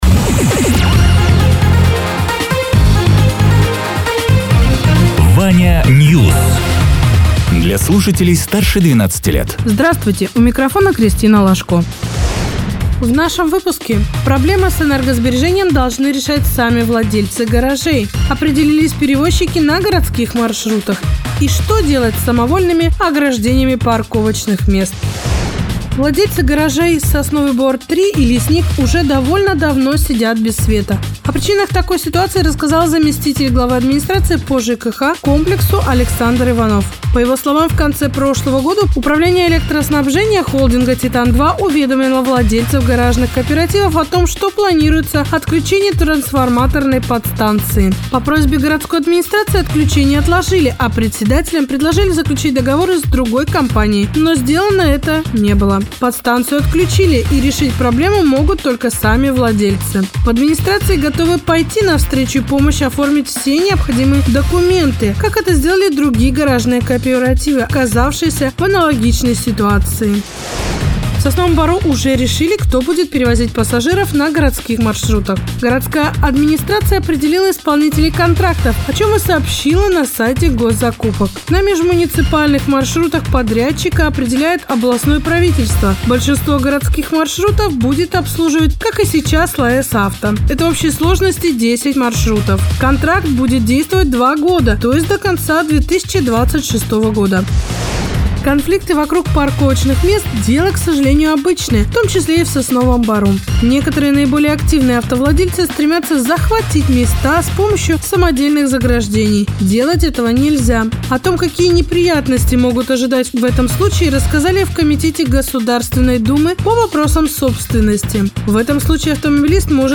Радио ТЕРА 12.04.2026_12.00_Новости_Соснового_Бора